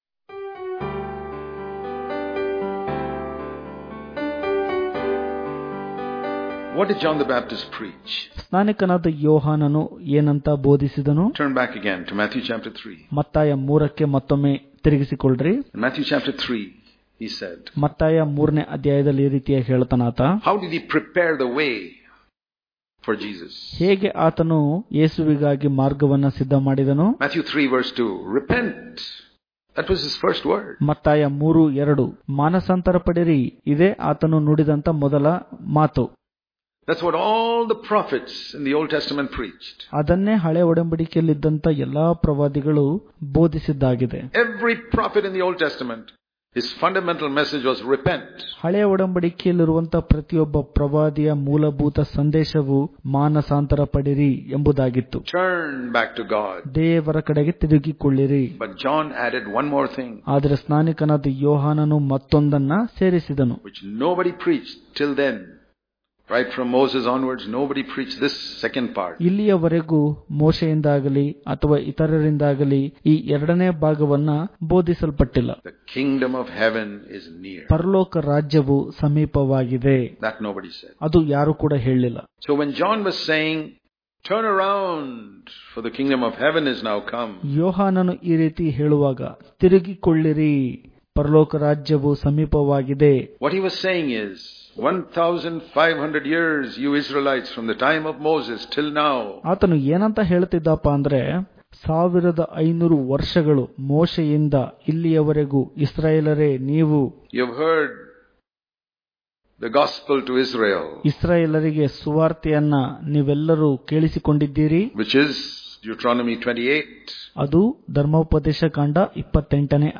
August 25 | Kannada Daily Devotion | We Must Submit Our Lives To The Kingdom Of God Daily Devotions